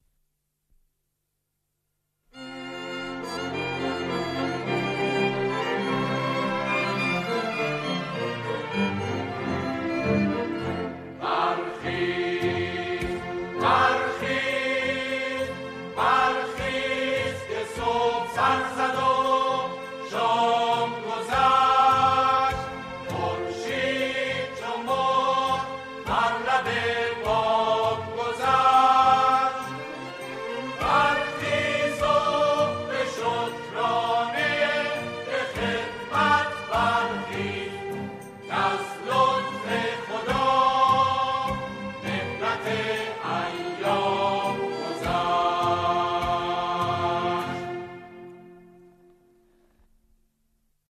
سرود کوتاه